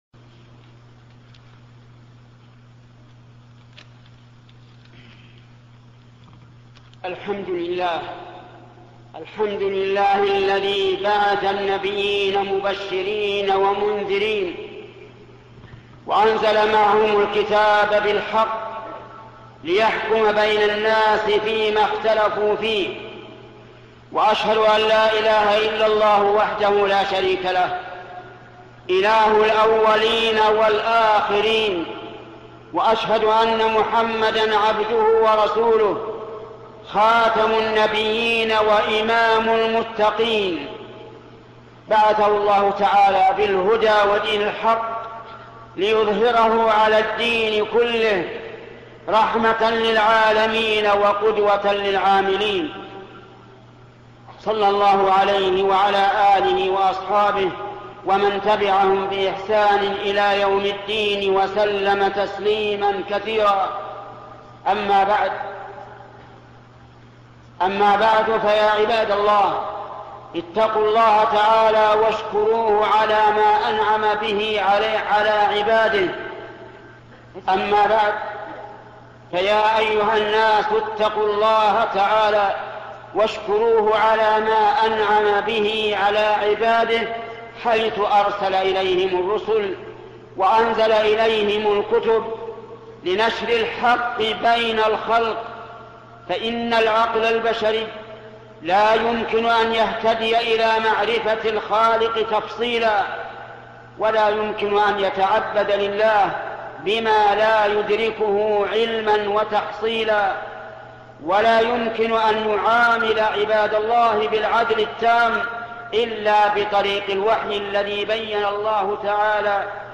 الشيخ محمد بن صالح العثيمين خطب الجمعة